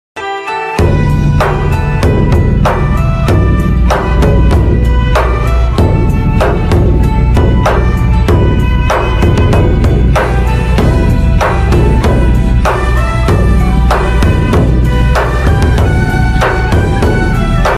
四川涼山彝族慶典活動！